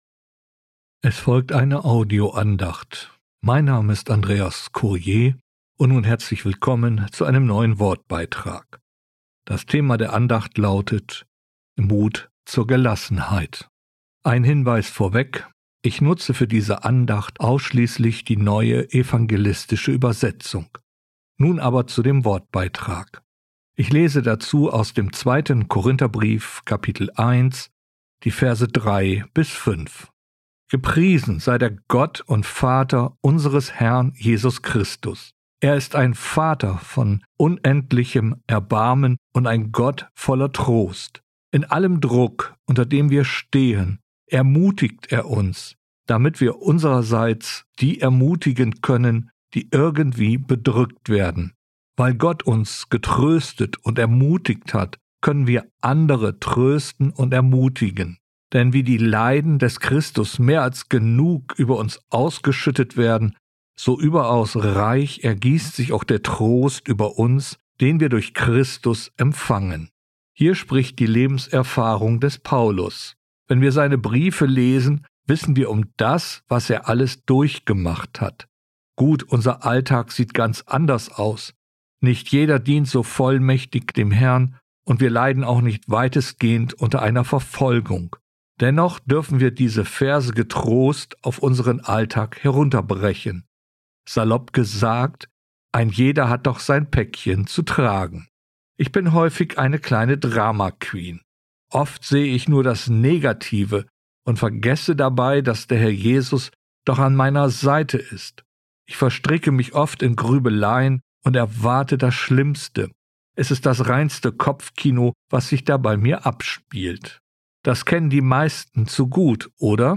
Mut zur Gelassenheit, eine Audio-Andacht